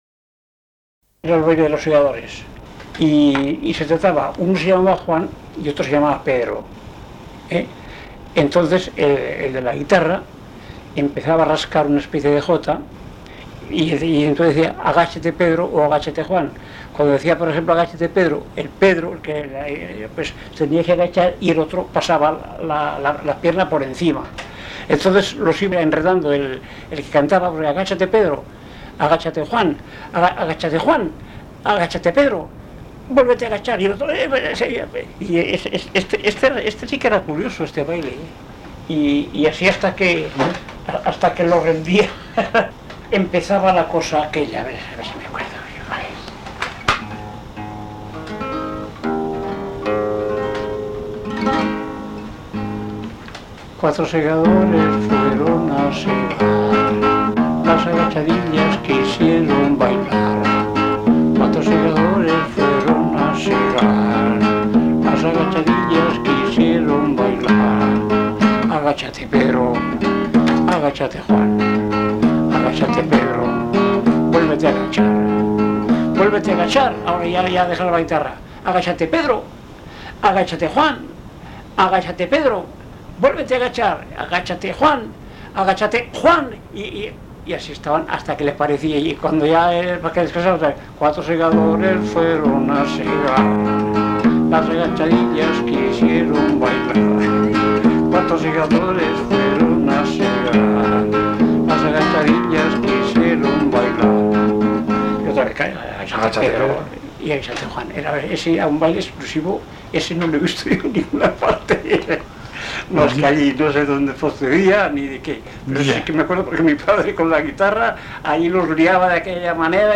Clasificación: Bailes
Lugar y fecha de recogida: Barcelona, 11 de junio de 1993